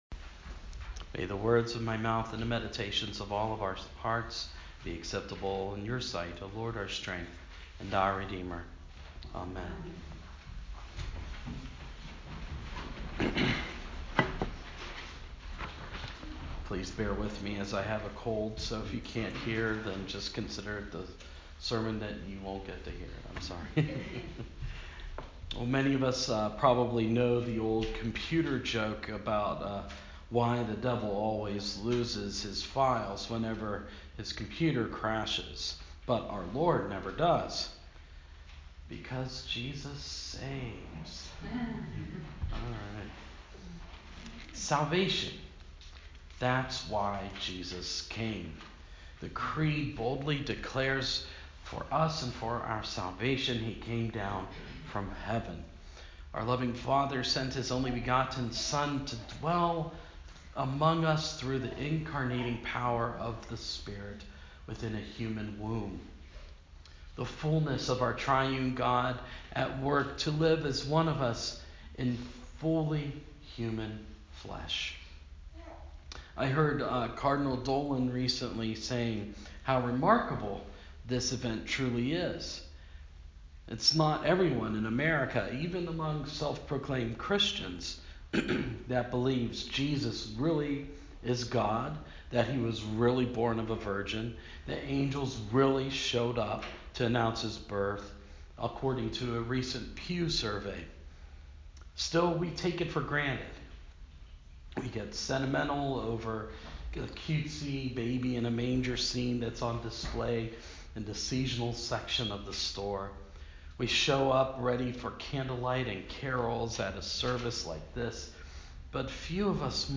Sore throat, but still preached from St Paul’s letter to Titus in the lectionary. Jesus came to save, and he saved us out of love.